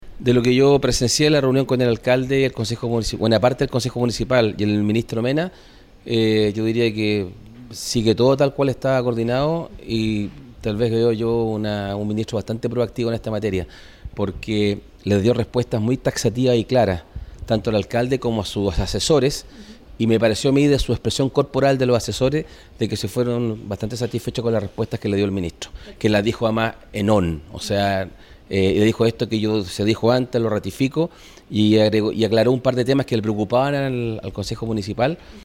CU-SENADOR-LAGOS-WEBER-MINISTRO-MEDIO-AMBIENTE.mp3